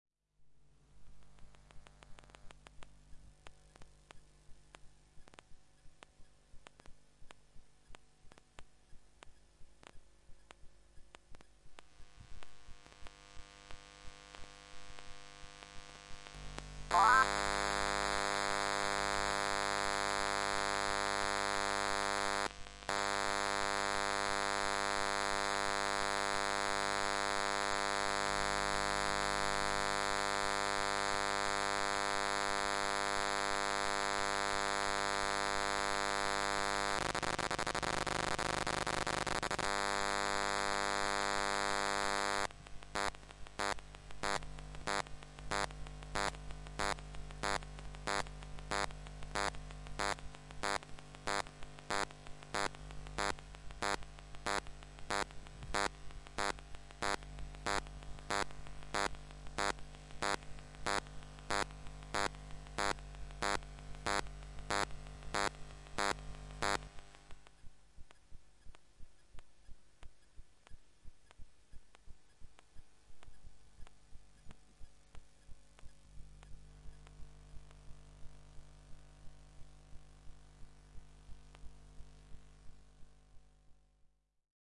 电磁场 " 家庭电话
描述：使用Zoom H1和电磁拾音器录制